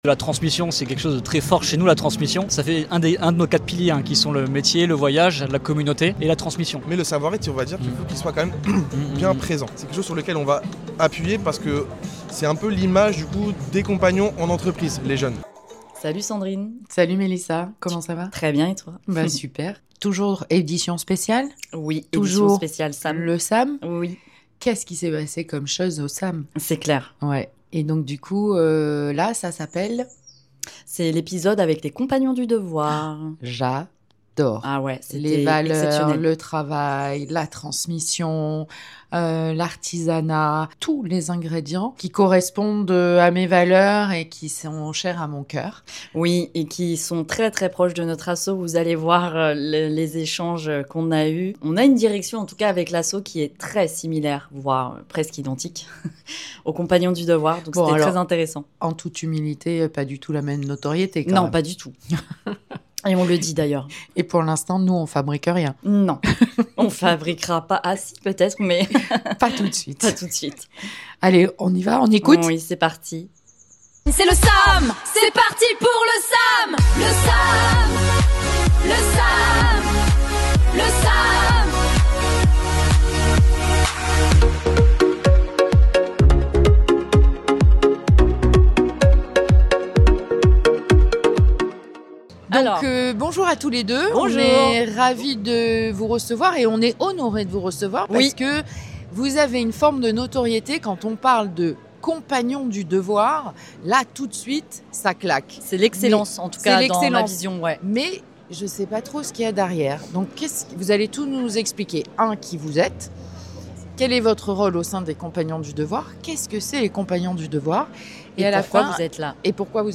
Dans cet épisode spécial enregistré dans le cadre du SAAM, nous partons à la rencontre des Compagnons du Devoir. Ici, le temps semble parfois suspendu, les gestes se répètent, les savoir-faire se transmettent, les anciens accompagnent les plus jeunes dans un véritable cheminement professionnel et personnel.